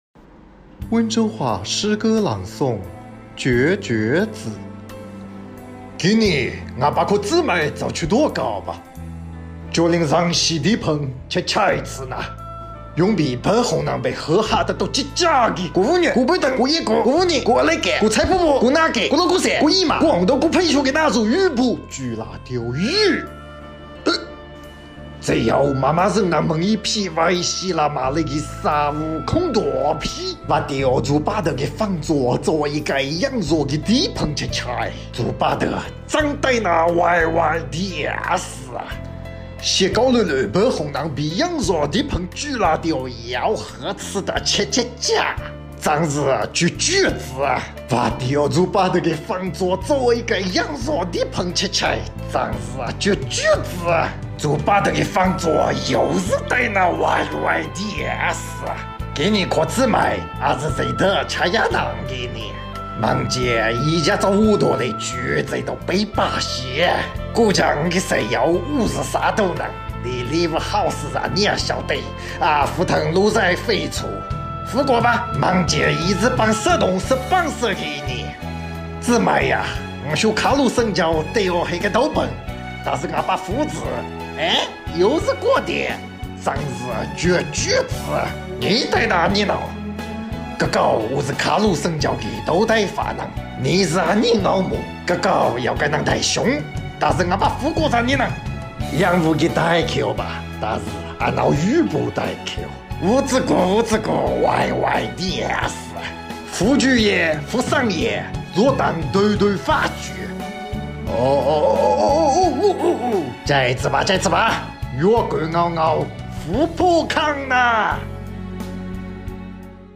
Wenzhou Dialect